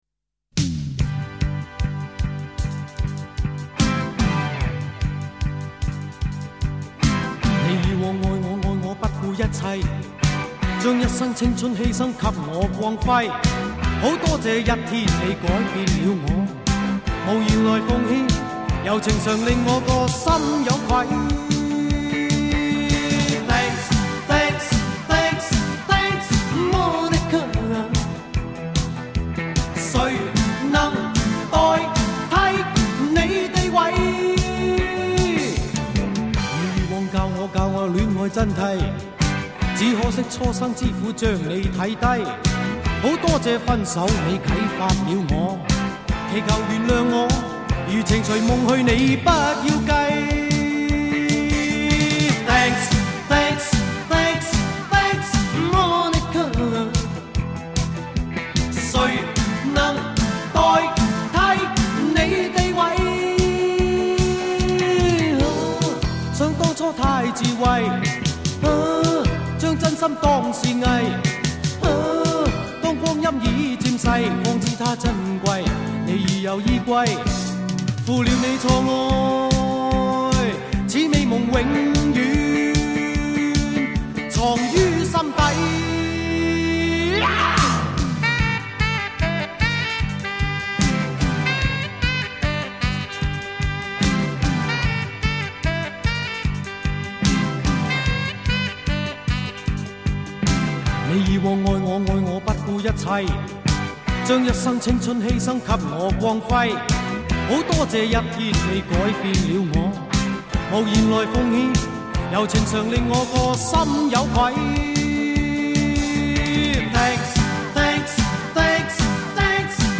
音乐风格: 流行